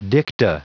Prononciation du mot dicta en anglais (fichier audio)